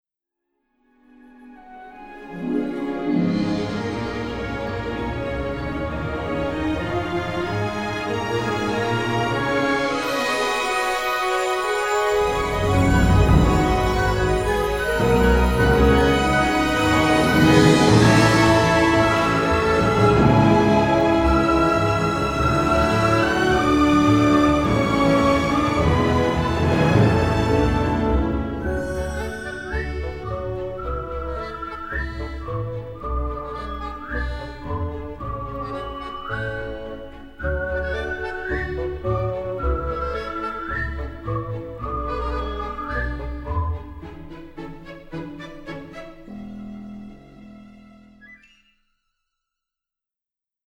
charming orchestral score